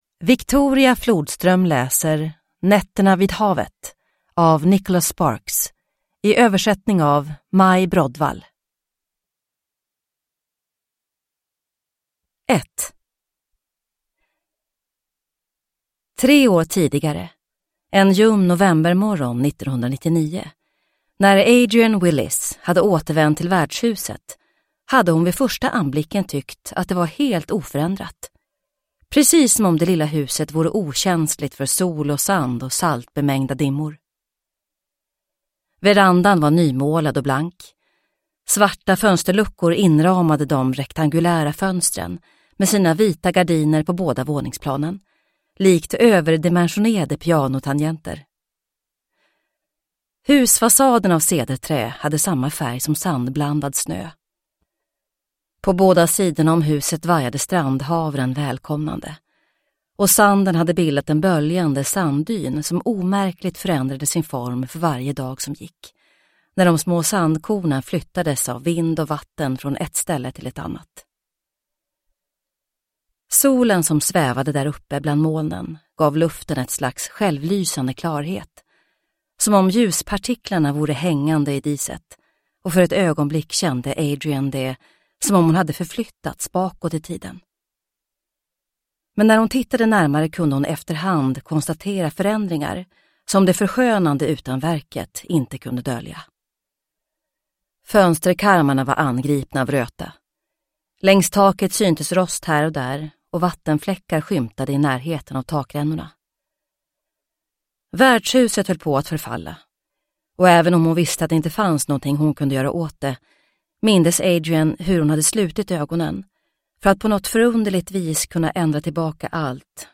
Nätterna vid havet – Ljudbok